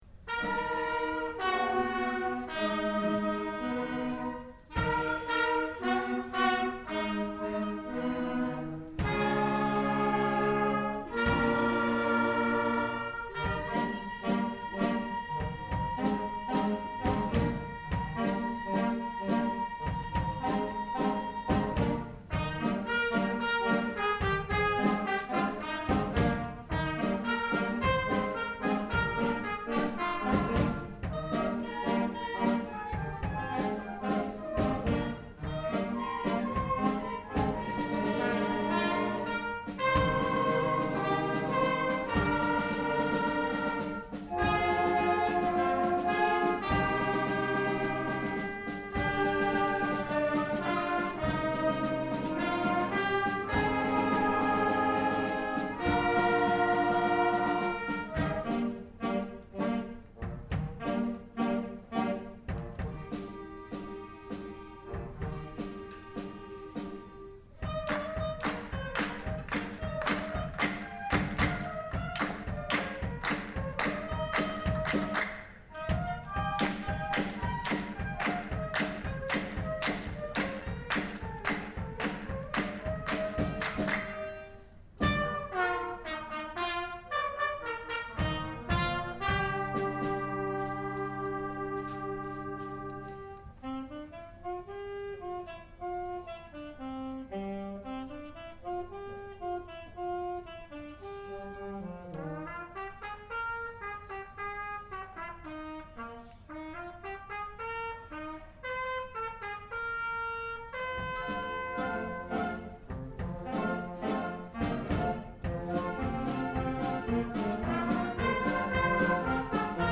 Rodeo - Junior Wind